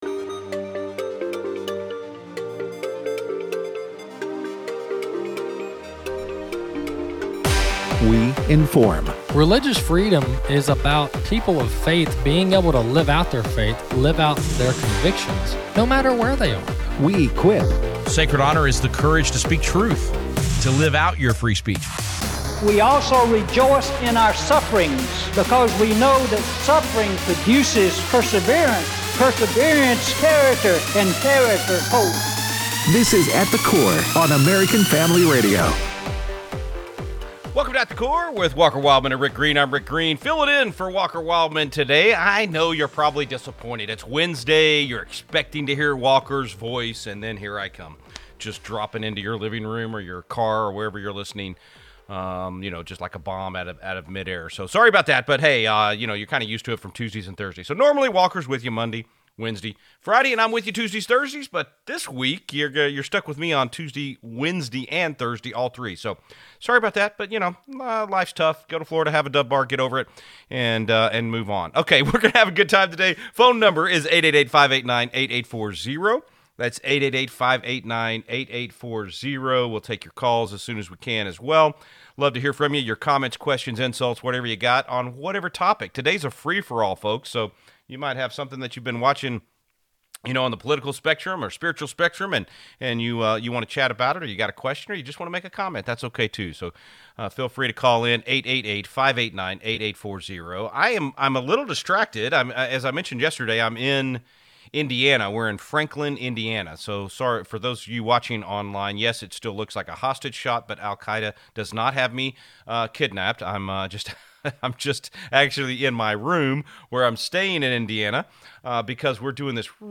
takes a call from a listener and gives us a brief history of Manifest Destiny. We need to tell the story of our nation’s founding heroes. 32:00 - 48:00.